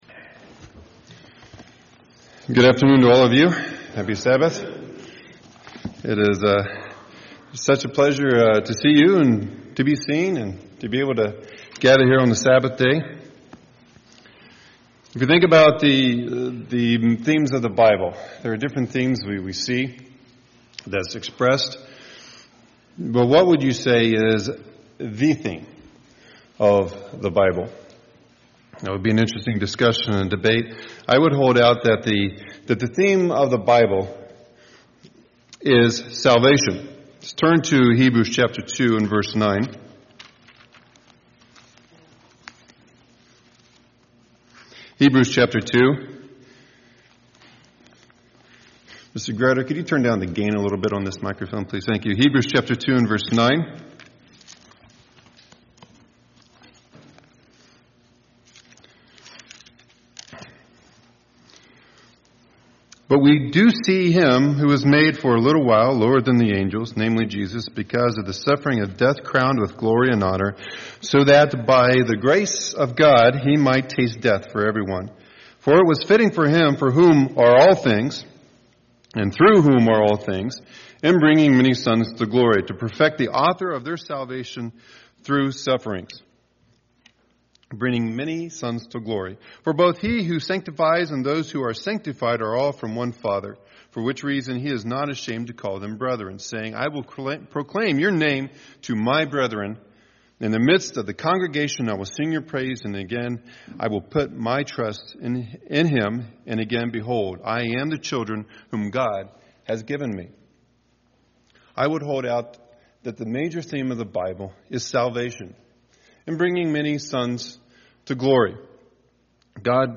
A message explaining the belief that the Church is a body of believers who have received, and are being led by, the Holy Spirit.
Given in Milwaukee, WI
UCG Sermon Studying the bible?